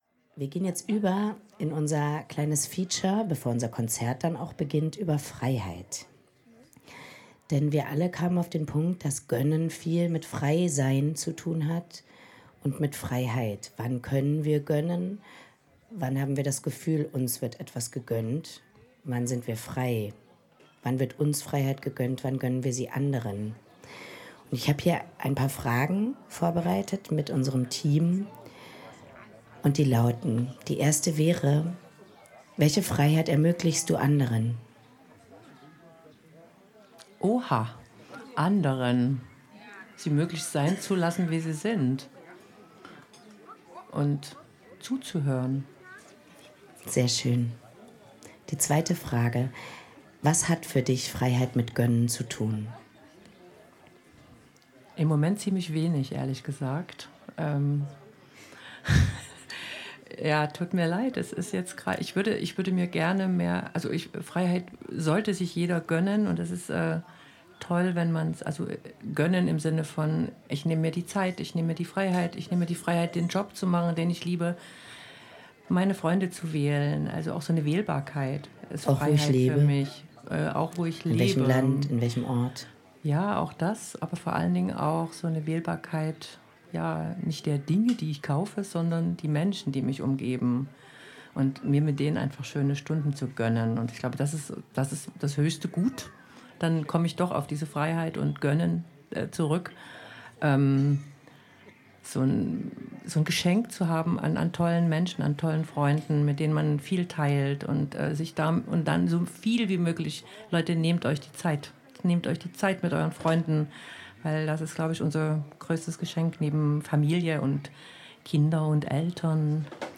Hier ist ein Interview nachzuhören, das beim Sommerfest GÖNNT EUCH der KulturKolchose Lehsten live gesendet wurde.